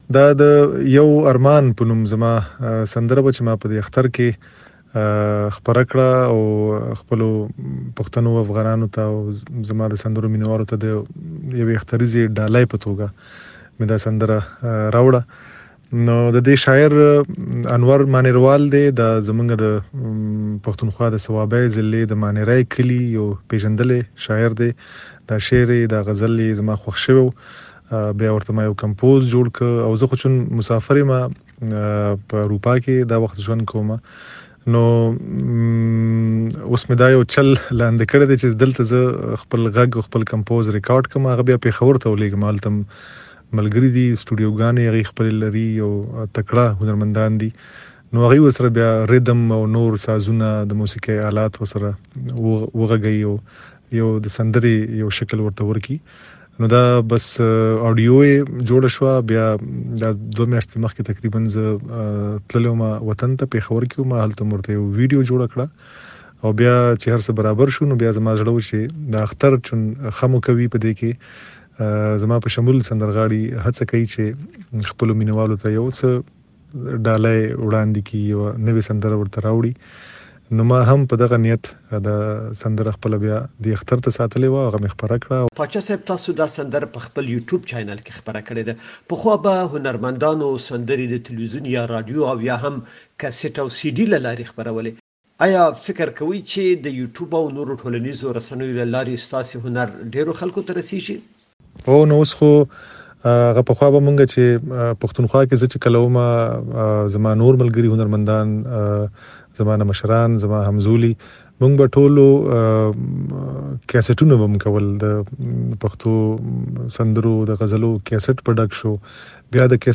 هارون باچا سره مرکه